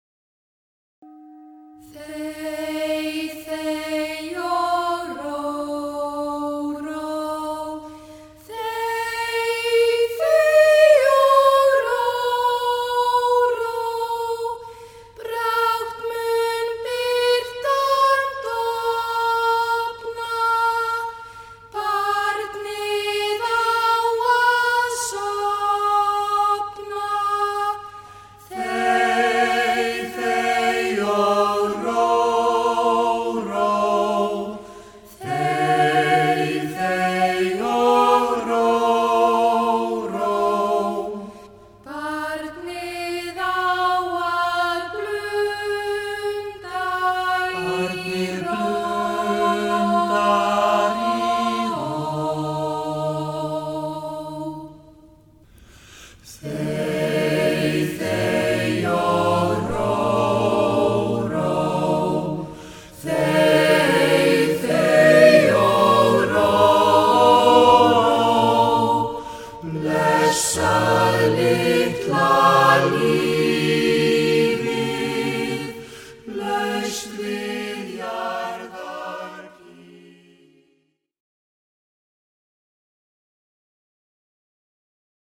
Leikarar úr Grandavegi 7 eftir Vigdísi Grímsdóttur syngja í hljóðdæminu
Pétur Grétarsson útsetti fyrir leikverkið sem var sýnt í Þjóðleikhúsinu.